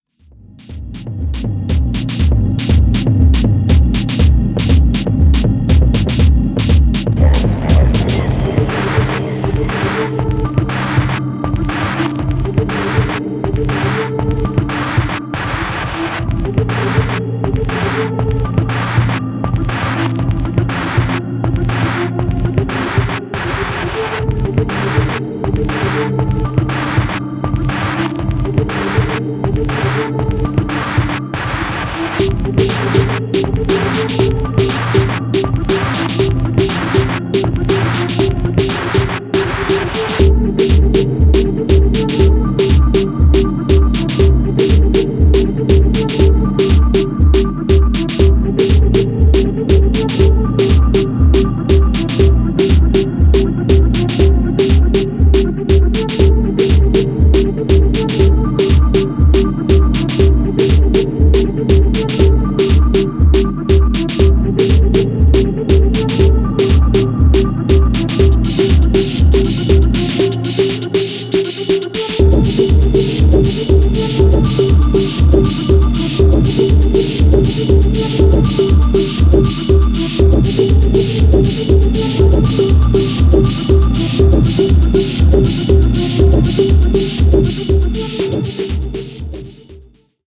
Real nice moody low fi techno tracks
Electronix Techno